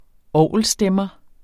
Udtale [ -ˌsdεmʌ ]